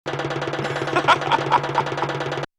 Bongo Laugh.wav